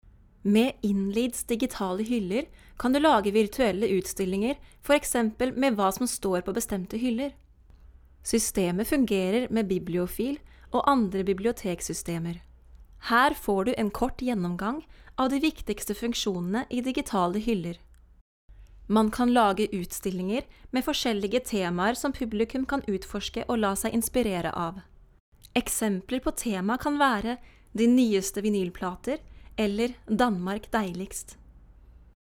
挪威语_NORWEGIAN. 这里都是挪威语母语老师，男声和女声。